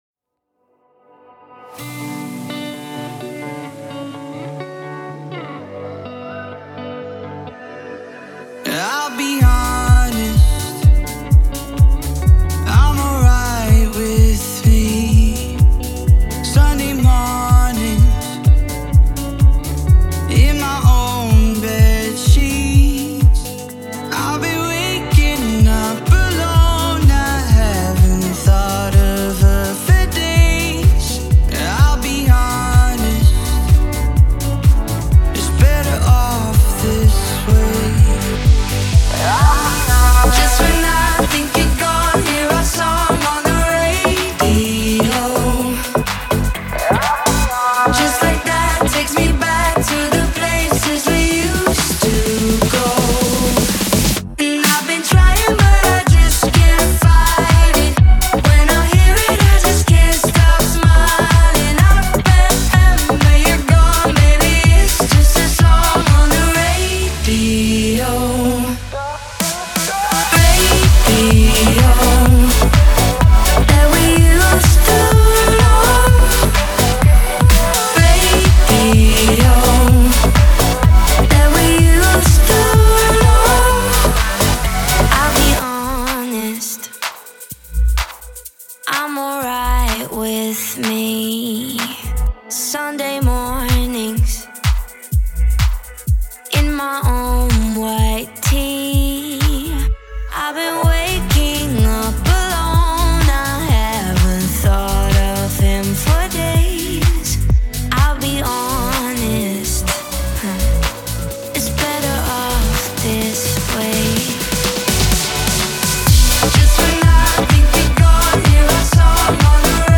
это энергичный поп-трек